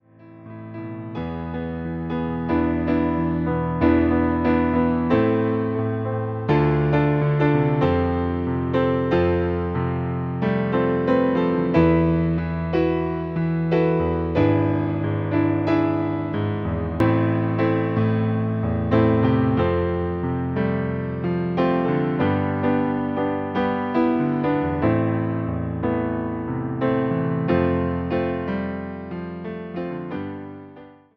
Wersja demonstracyjna:
91 BPM
a – moll